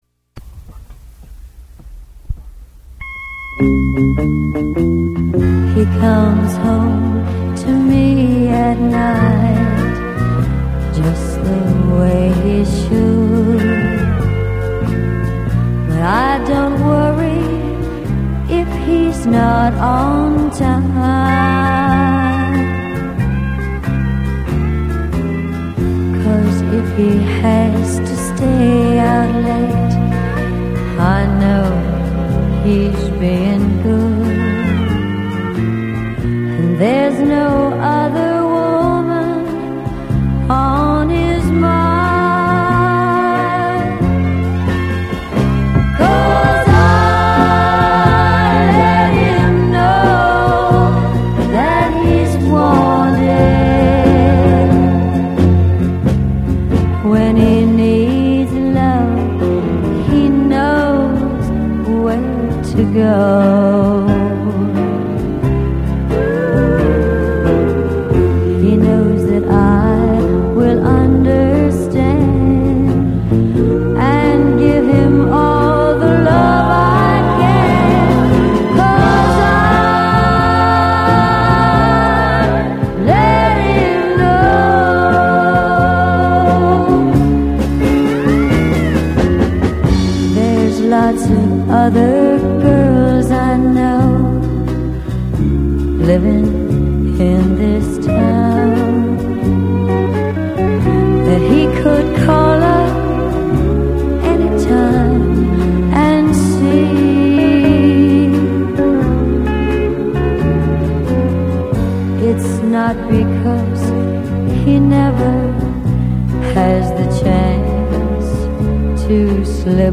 in  at RCA Studio B in Nashville, TN.